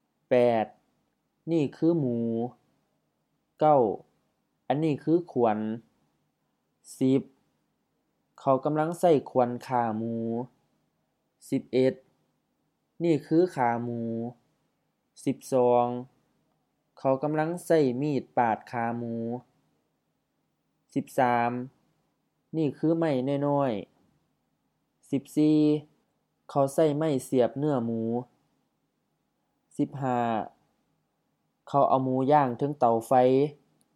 Notes: pronunciation: also realized as เนื้อ
Notes: alternative pronunciation ญ้าง (HF)